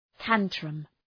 Προφορά
{‘tæntrəm}